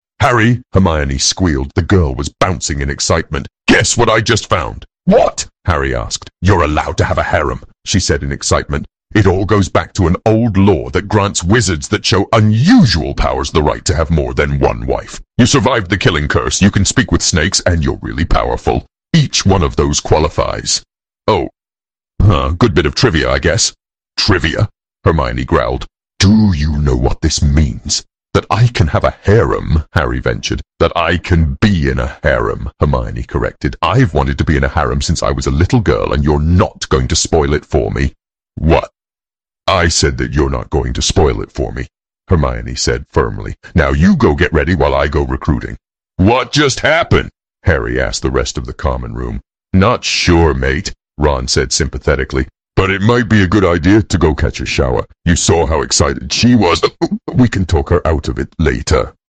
It switches between American and British like it's going out of style.